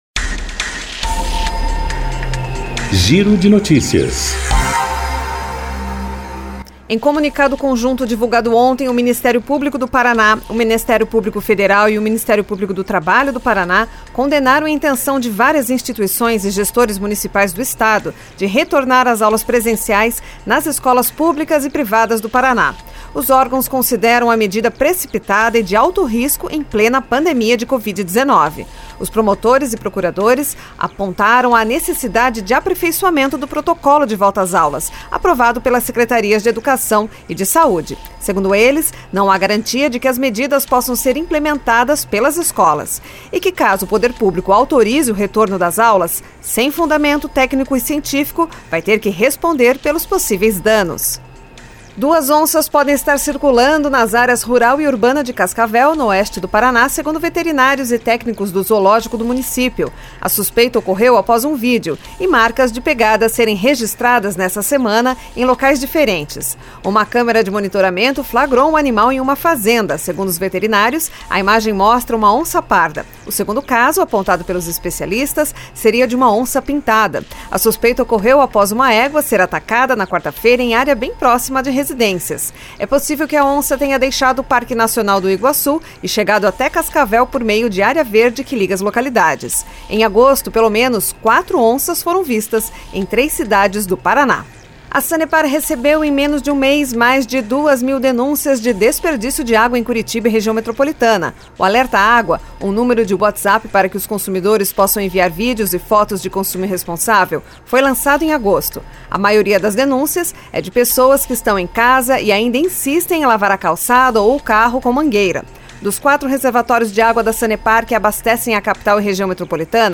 Giro de Notícias COM TRILHA